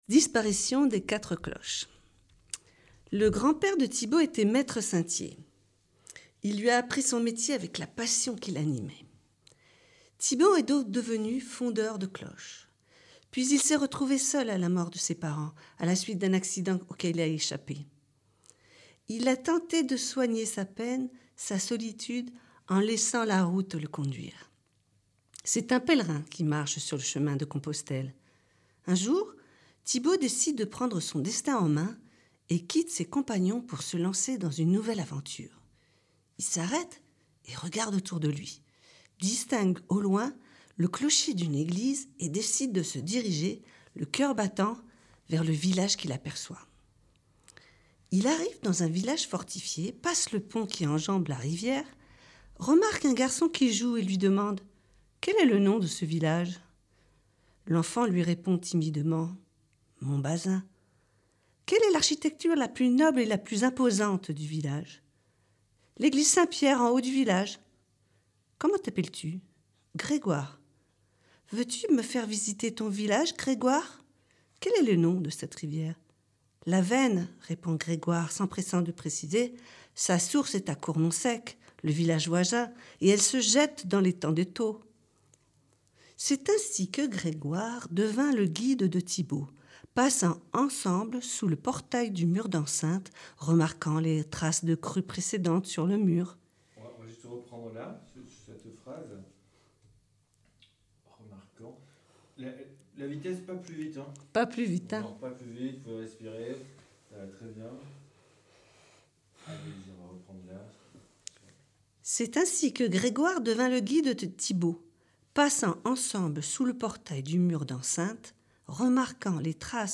Entre réalité et fiction, les jeunes et moins jeunes de Montbazin ont été invités à plonger au cœur de leur village, de ses habitants mais aussi de leur imaginaire pour y créer leurs légendes, les mettre en voix et en son pour une retransmission in-situ, à la croisée des arts.